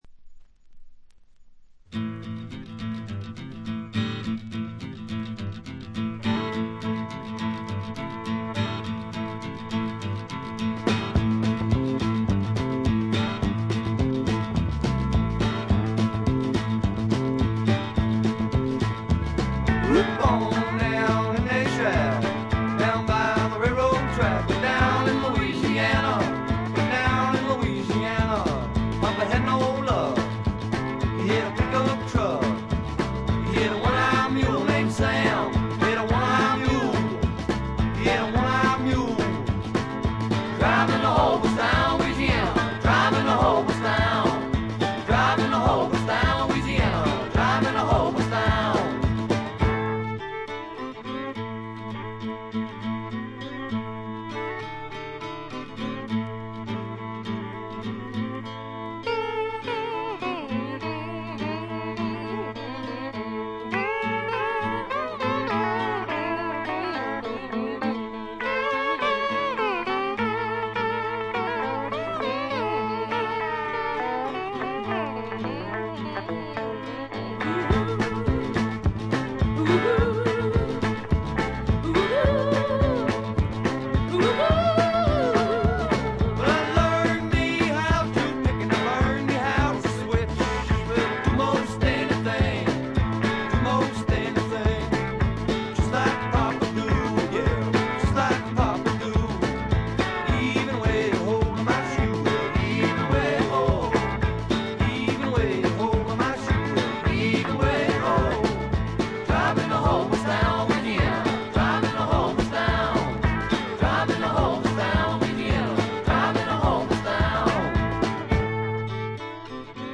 ほとんどノイズ感無し。
ニューヨーク録音、東海岸スワンプの代表作です。
いかにもイーストコーストらしい機知に富んだスワンプアルバムです。
試聴曲は現品からの取り込み音源です。
Banjo, Guitar (Steel)